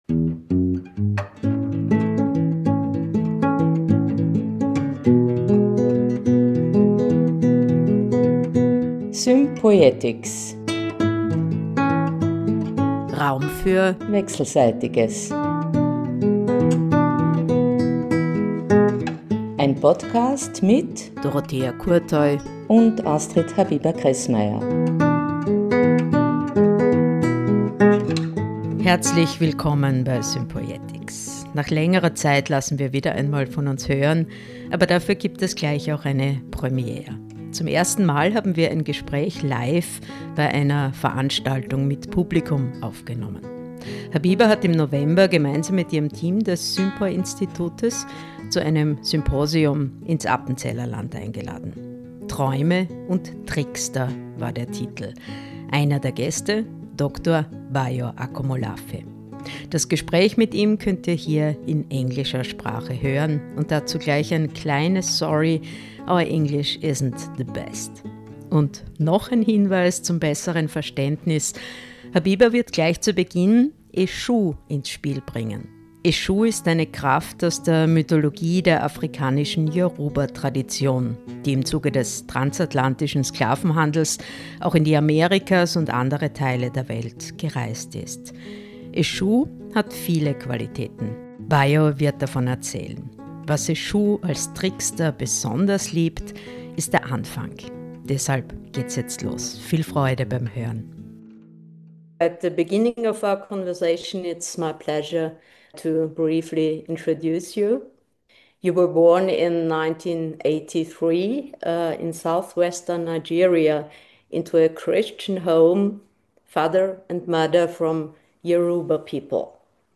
Dieses Gespräch wurde im Rahmen der Tagung „Träume und Trickster“ des Sympoi Instituts im November 2025 im Appenzellerland in der Schweiz aufgenommen.